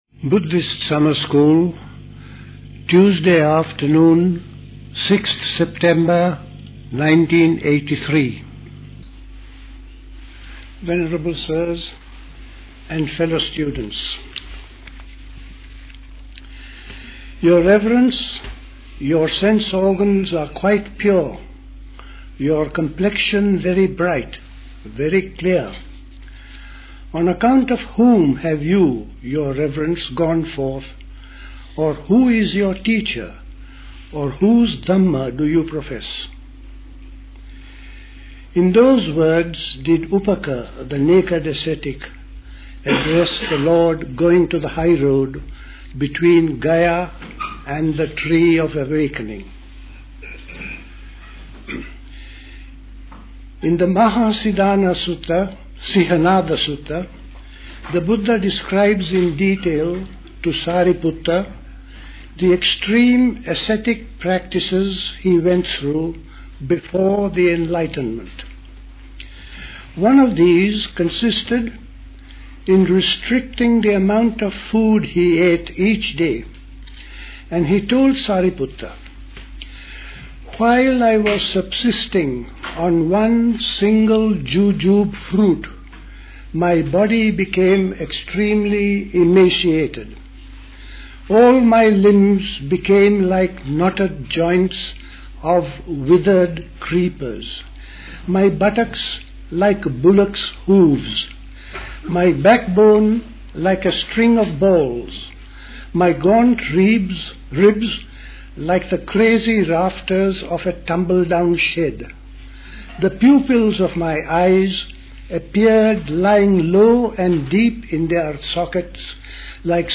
The Buddhist Society Summer School Talks